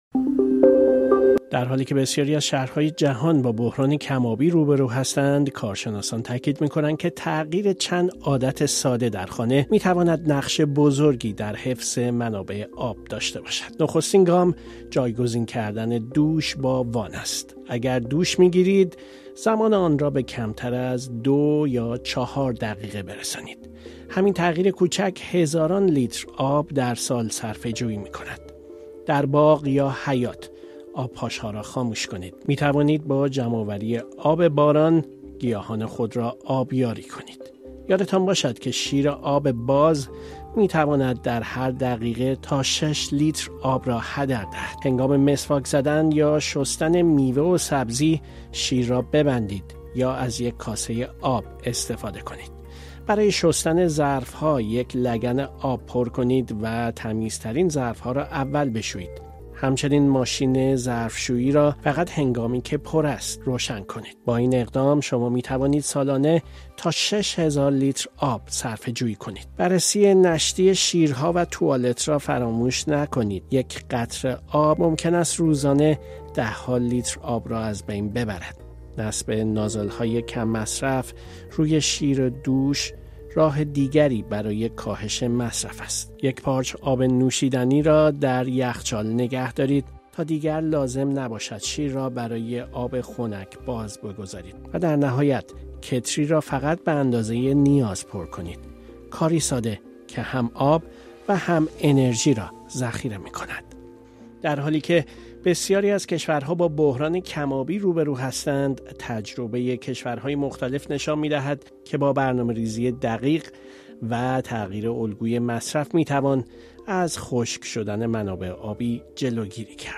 گزارشی در این زمینه بشنوید.